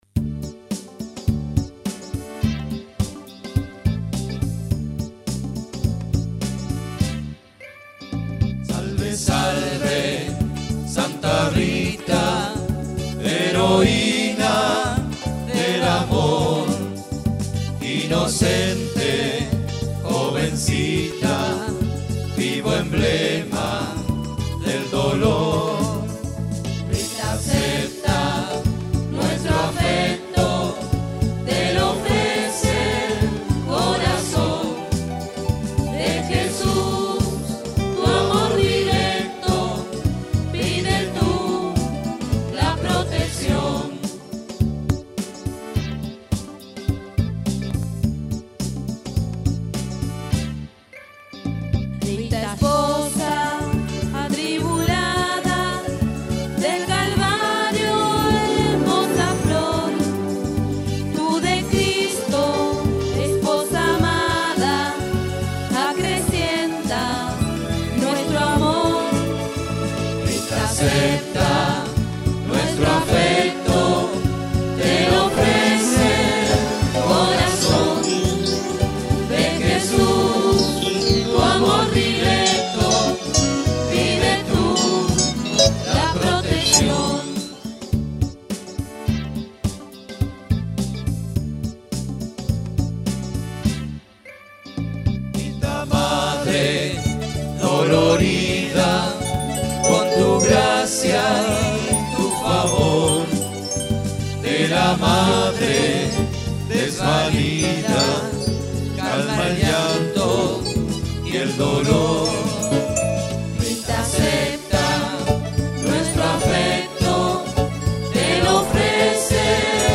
Himno a Santa Rita
Himno-a-Santa-Rita.mp3